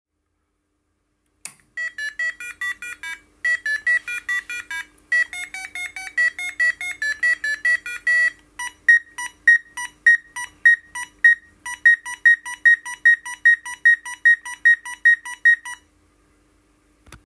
Beim Einschalten ertönt als akustisches Erkennungszeichen eine kurze Titelmusik über den eingebauten Piezo-Summer.